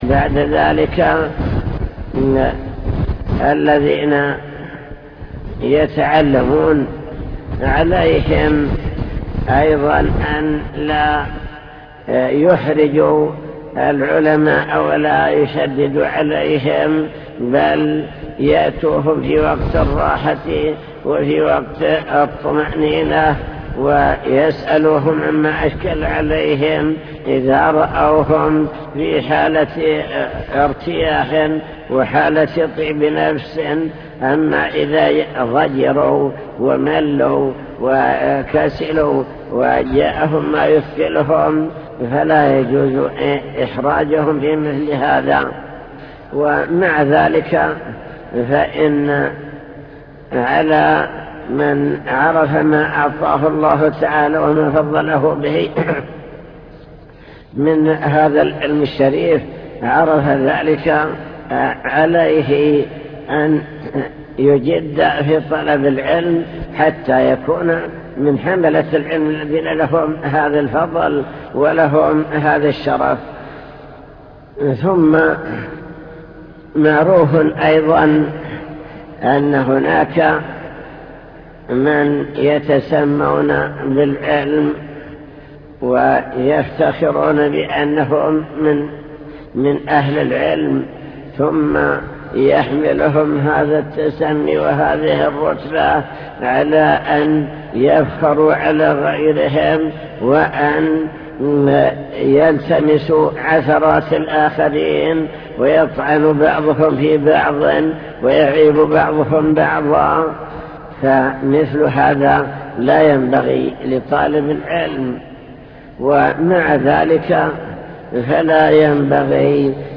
المكتبة الصوتية  تسجيلات - لقاءات  لقاء مفتوح مع الشيخ